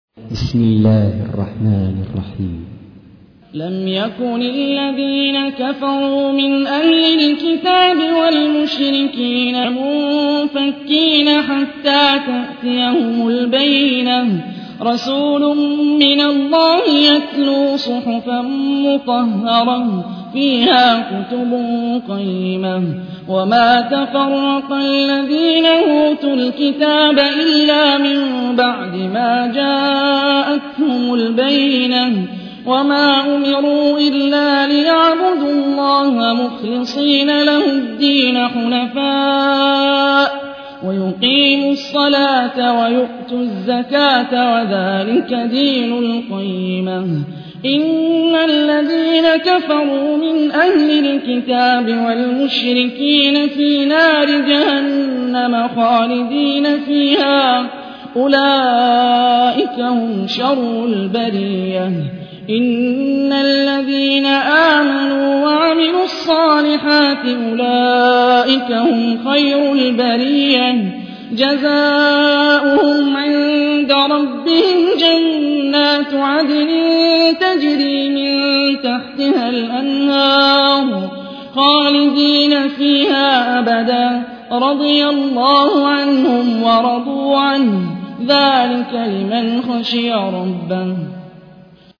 تحميل : 98. سورة البينة / القارئ هاني الرفاعي / القرآن الكريم / موقع يا حسين